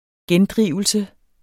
Udtale [ ˈgεnˌdʁiˀvəlsə ]